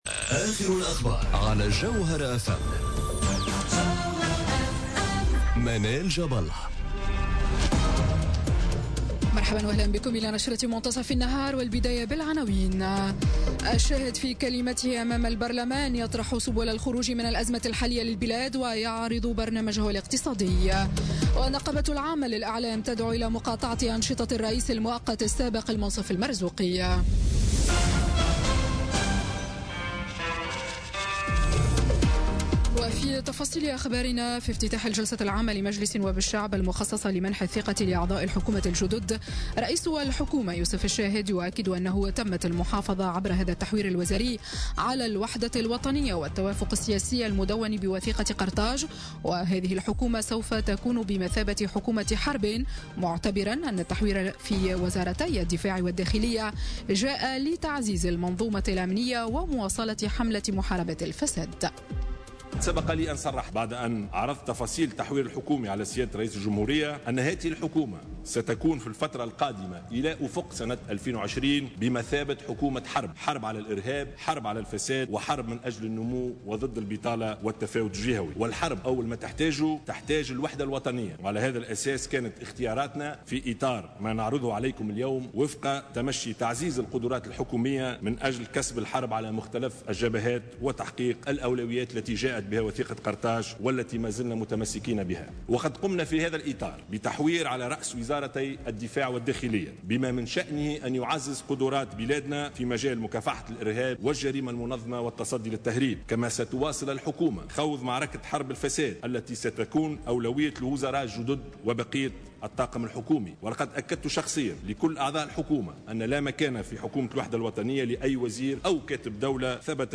نشرة الأخبار منتصف النهار ليوم الإثنين 11 سبتمبر 2017